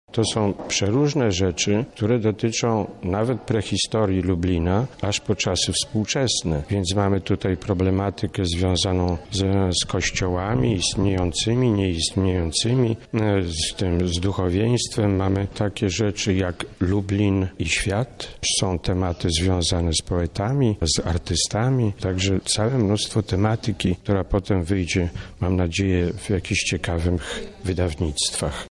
jeden z organizatorów.